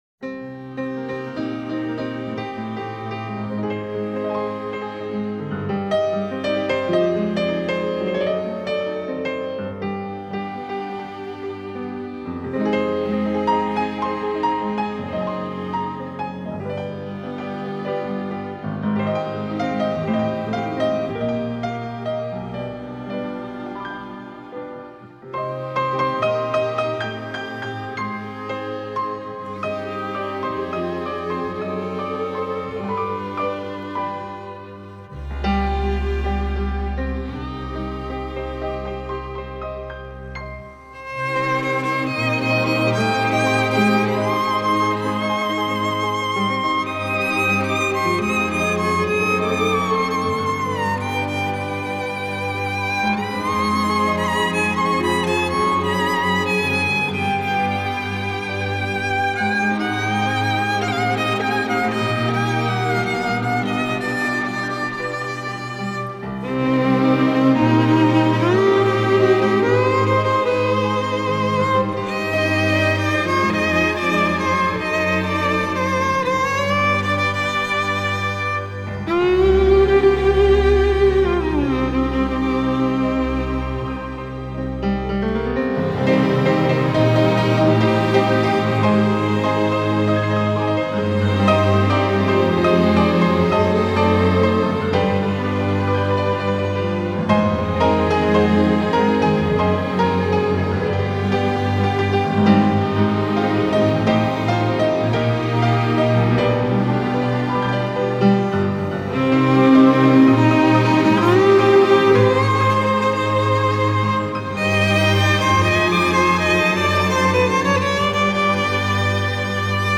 آهنگ بی‌کلام